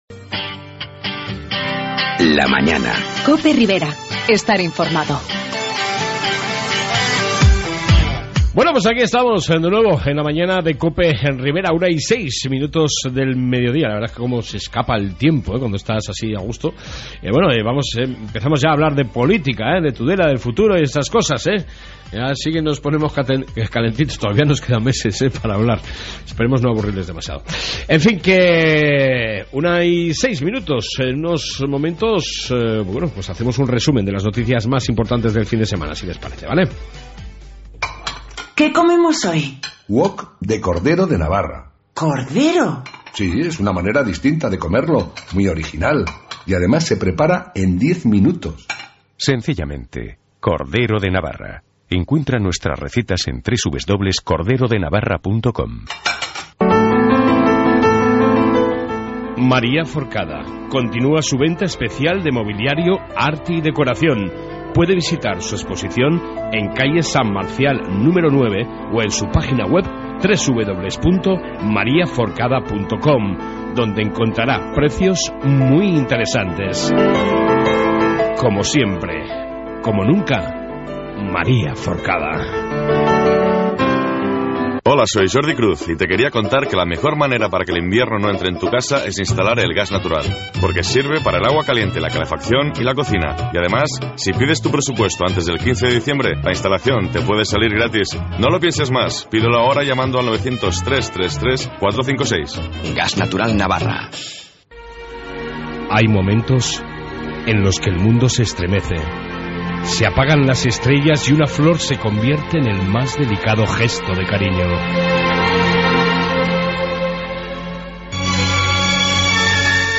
AUDIO: en esta 2 parte Amplio informativo ribero